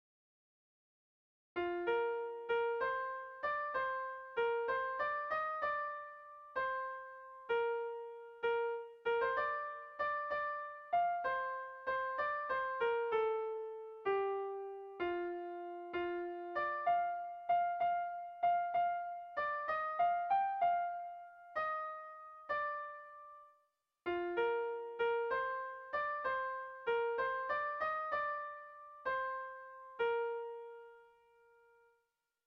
Kontakizunezkoa
ABDB